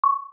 Listen to the same 1121 Hz tone combined with a 1099 Hz tone of the same profile